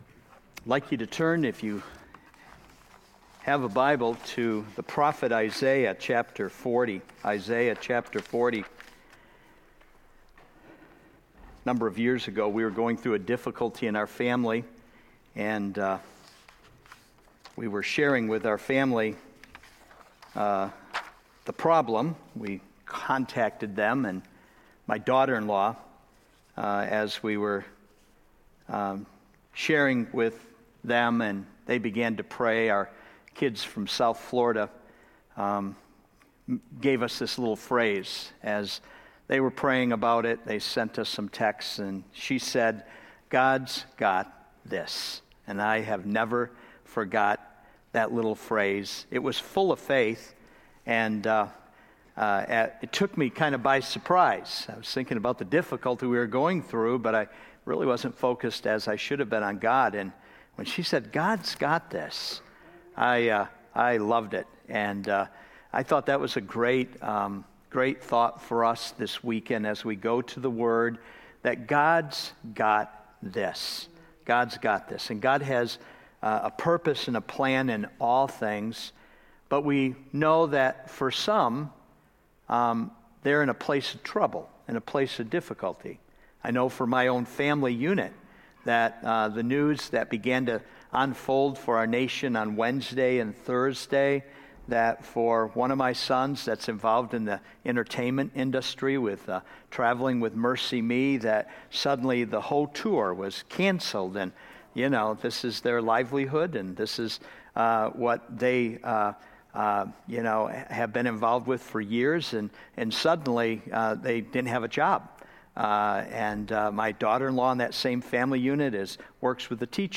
Genre Sermon or written equivalent